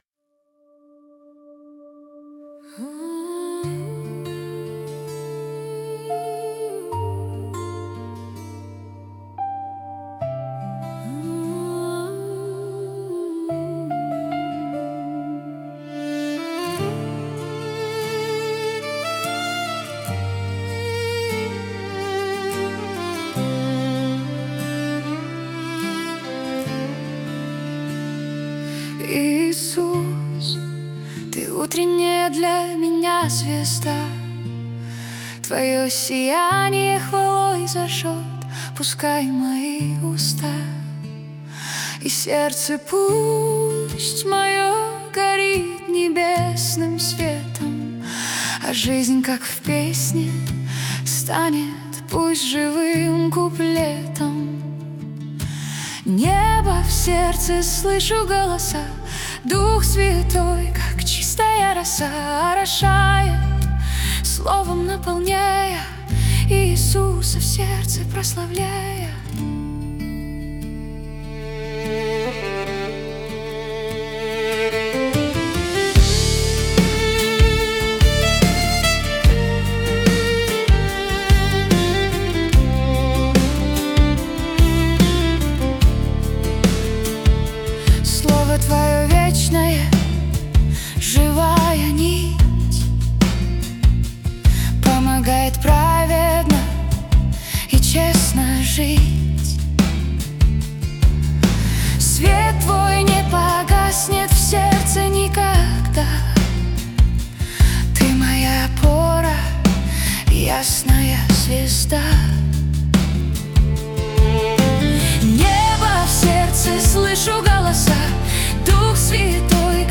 песня ai
456 просмотров 3476 прослушиваний 166 скачиваний BPM: 73